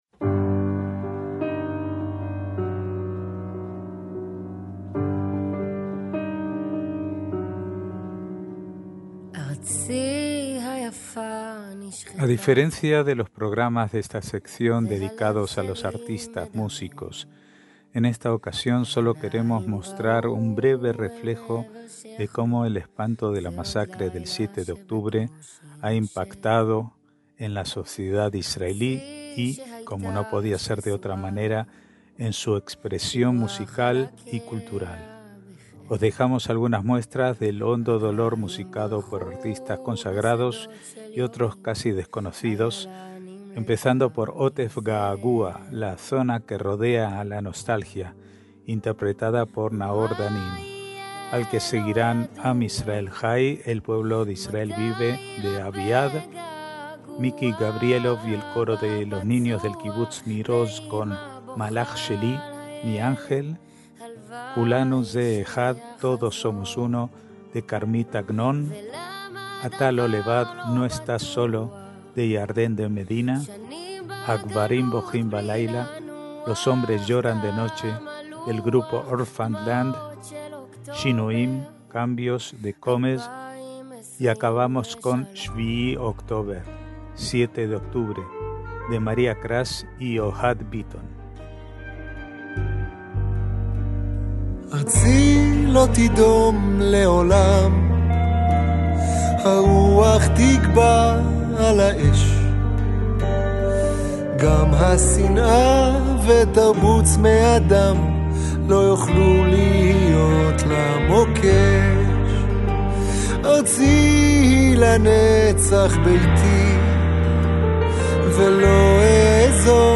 MÚSICA ISRAELÍ – A diferencia de los programas de esta sección dedicados a los artistas músicos, en esta ocasión sólo queremos mostrar un breve reflejo de cómo el espanto de la masacre del 7 de octubre ha impactado en la sociedad israelí y, como no podía ser de otra manera, en su expresión cultural y musical.